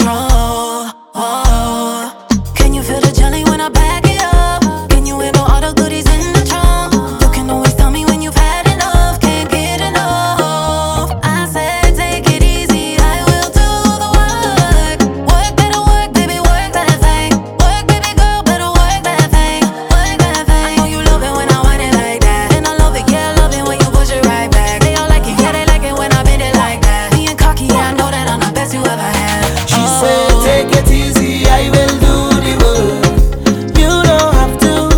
Off-beat гитары и расслабленный ритм
Жанр: Регги